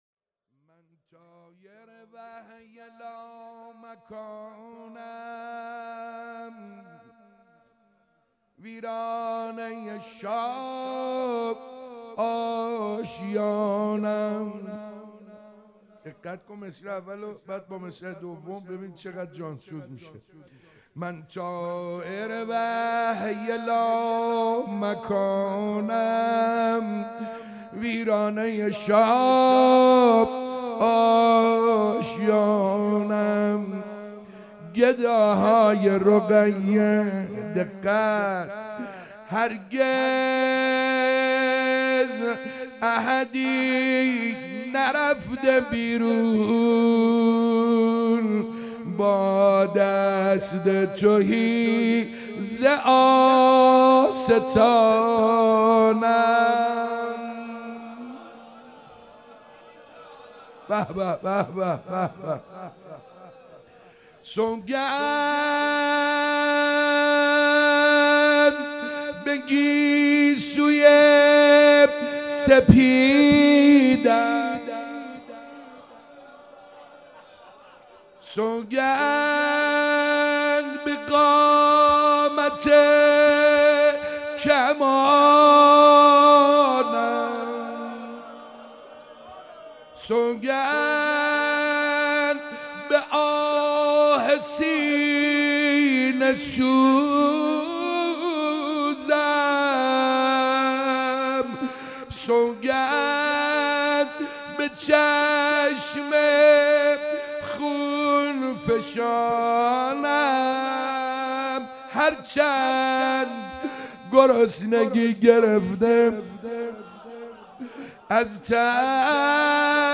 روضه - من طائر وحی لو مکانم